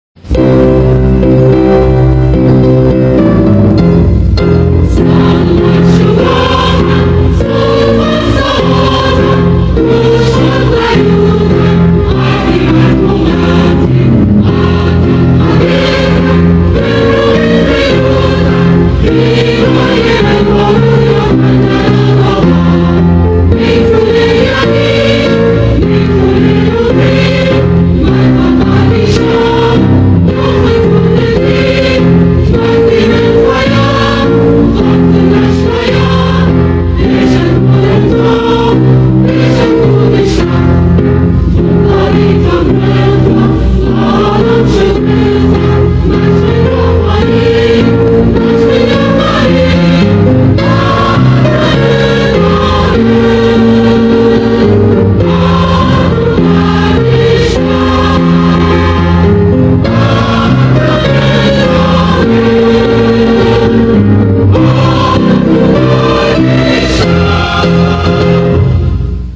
Salma d'Shoobakhan is one of two de facto national anthems of the Assyrian people.